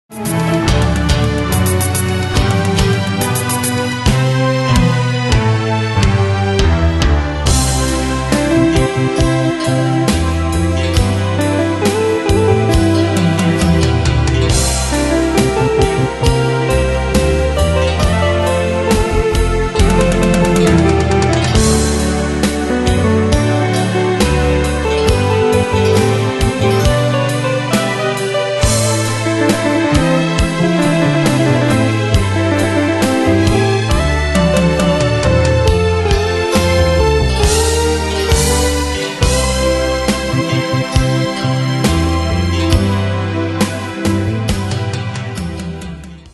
Style: Oldies Année/Year: 1969 Tempo: 64 Durée/Time: 3.08
Danse/Dance: Ballad Cat Id.
Pro Backing Tracks